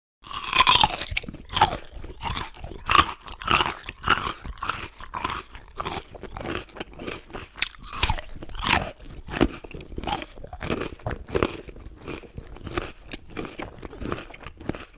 Хруст моркови при кусании, жевание, нарезка и другие звуки в mp3 формате
6. Звук жевания и кусания морковки